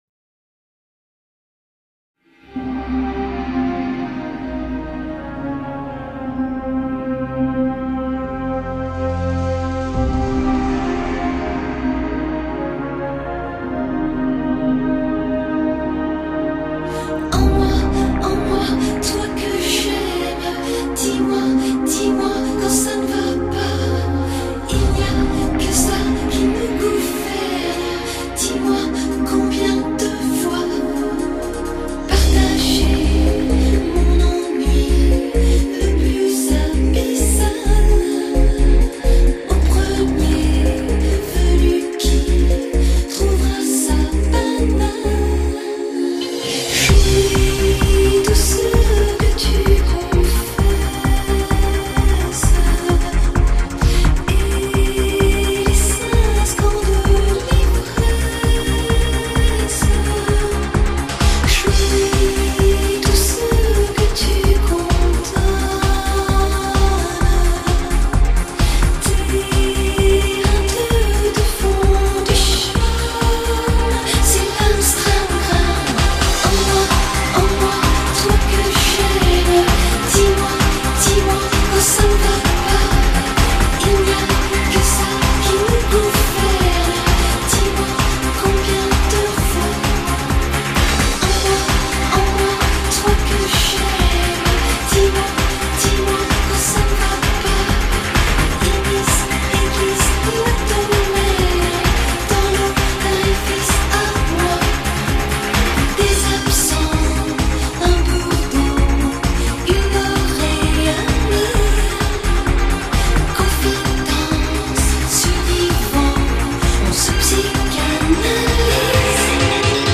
Genre: Pop, Pop-Rock, Electronica